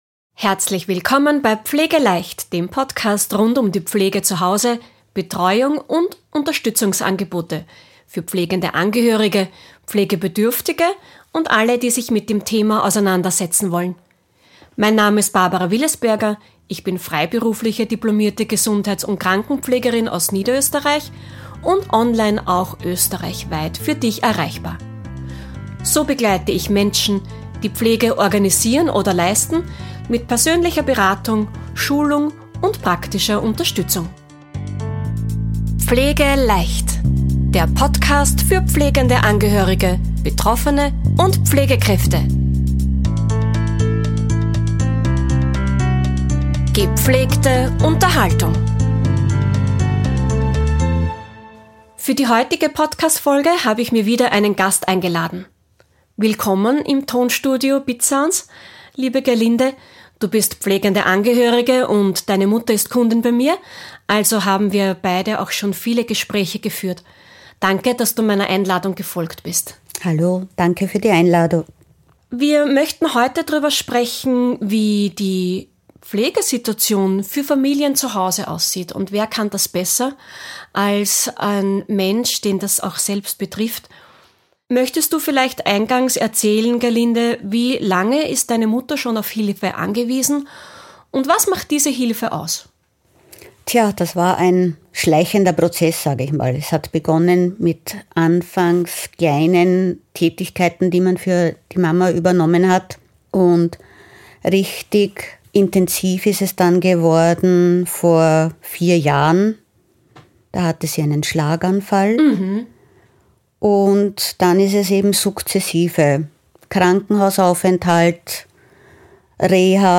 Interview mit einer pflegenden Tochter #27 ~ PflegeLEICHT! Podcast